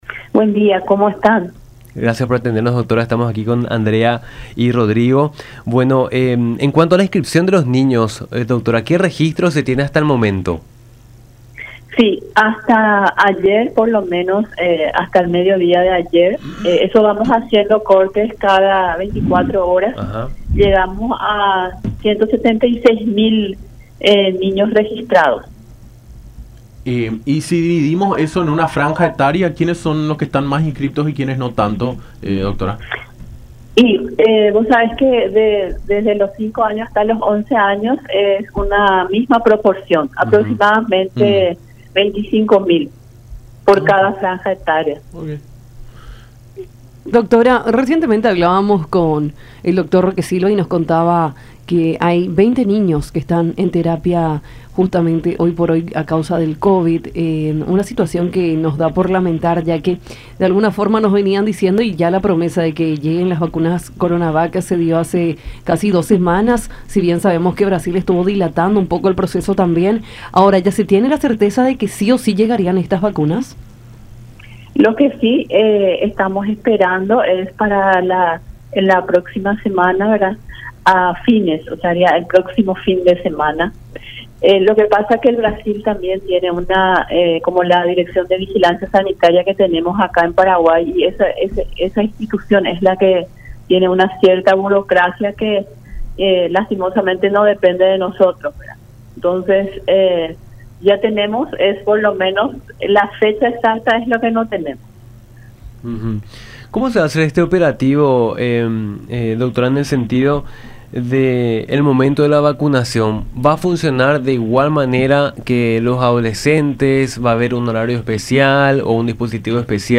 Zully Suárez, directora de Niñez del Ministerio de Salud, en comunicación con Nuestra Mañana por La Unión, en referencia al registro en la plataforma web Vacunate, de la cartera sanitaria.